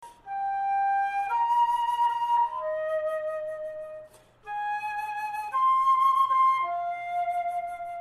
Tune